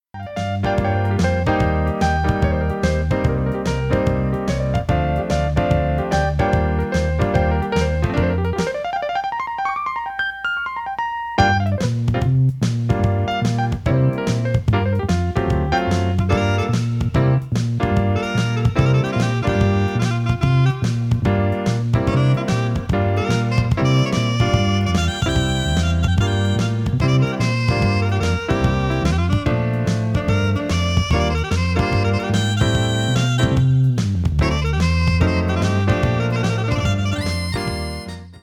screaming piano and hammond solos
accompianment from a backing band